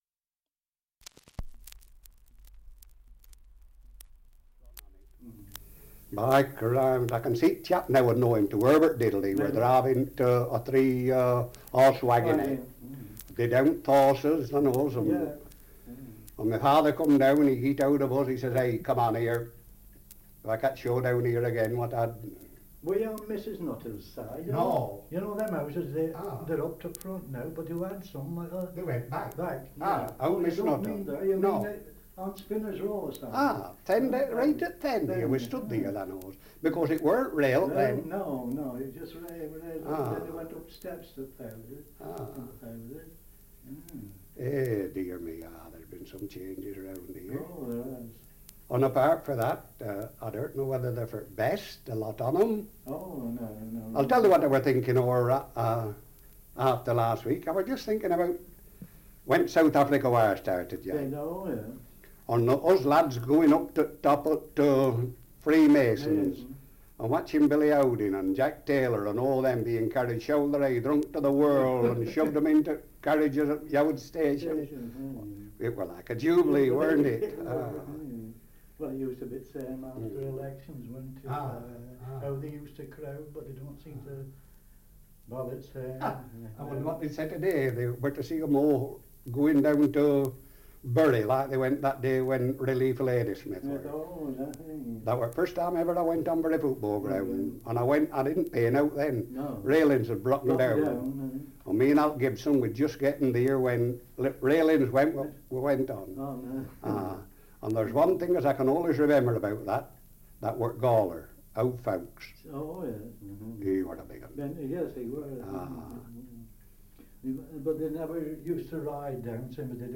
Dialect recording in Heywood, Lancashire
78 r.p.m., cellulose nitrate on aluminium